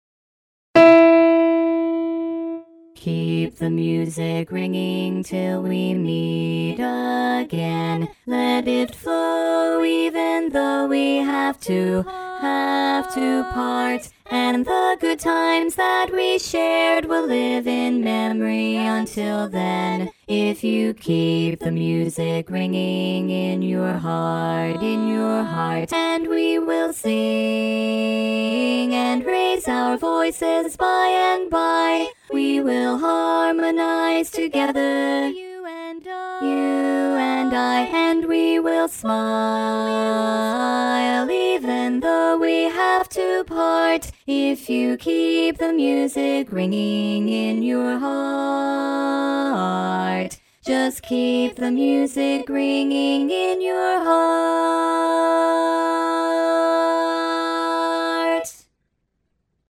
To download the learning tracks/chart that you need, right click on the name of the file below and choose "Save target as" or something like that depending on what browser you are using.
KeepTheMusicRinging-E-Bass Dom.mp3